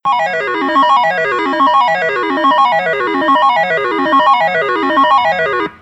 jackpot.wav